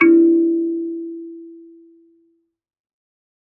kalimba2_wood-E3-pp.wav